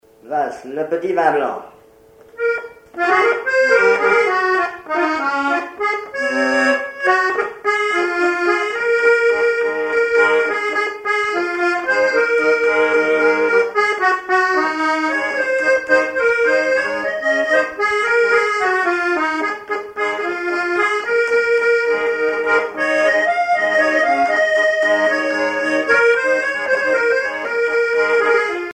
accordéon(s), accordéoniste
danse : valse musette
Pièce musicale inédite